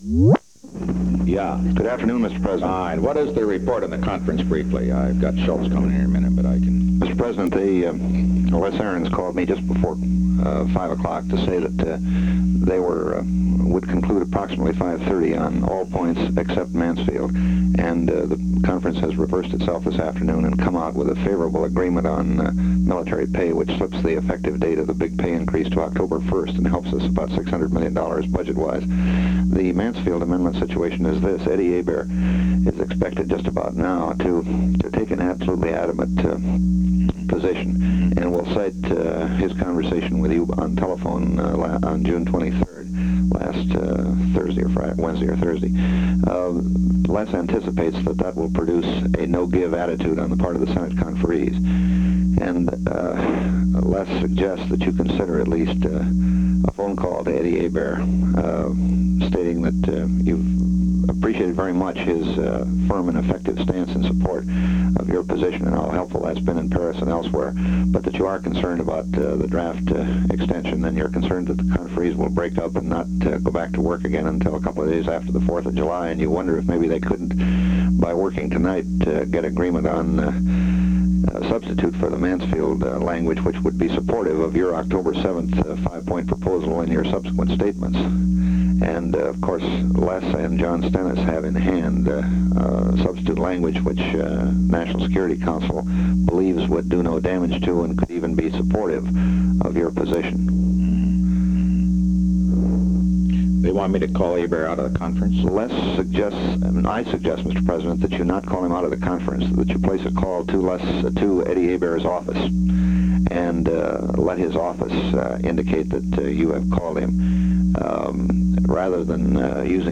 Secret White House Tapes
Location: White House Telephone
The President talked with Clark MacGregor.